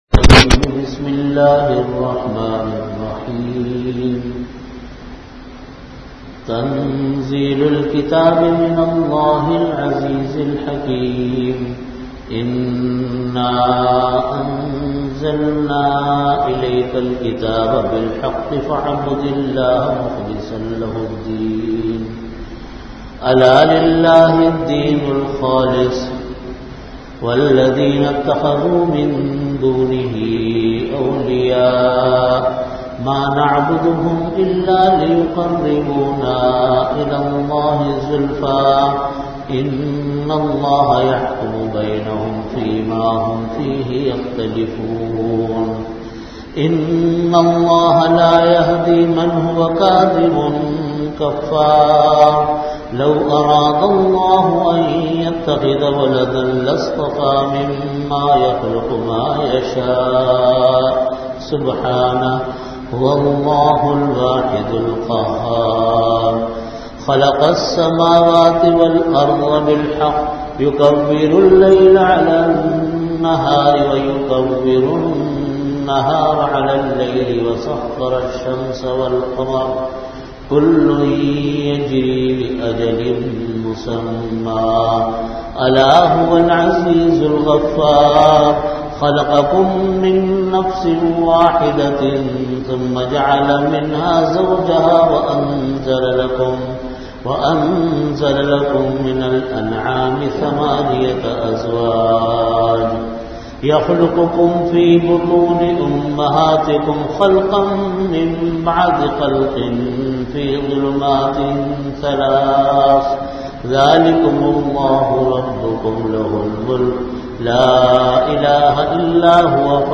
Category: Tafseer
Time: After Asar Prayer Venue: Jamia Masjid Bait-ul-Mukkaram, Karachi